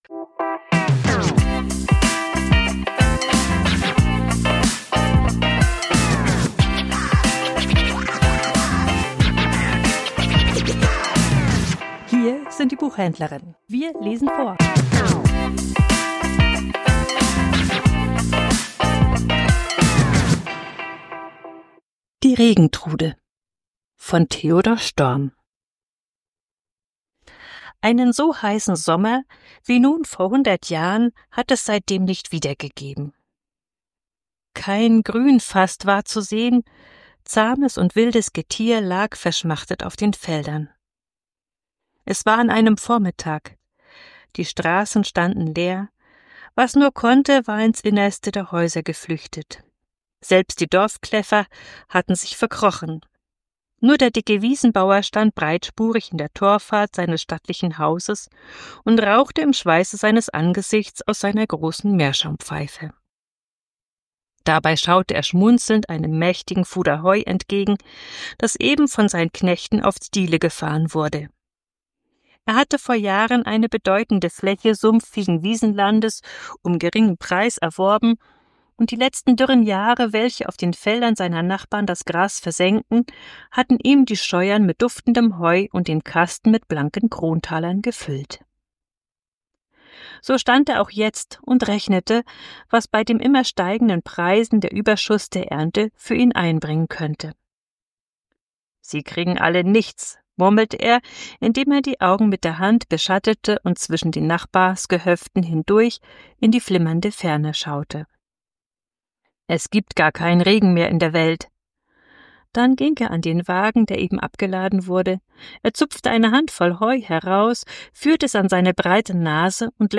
Vorgelesen: Die Regentrude ~ Die Buchhändlerinnen Podcast